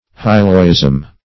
Hyloism \Hy"lo*ism\, n.
hyloism.mp3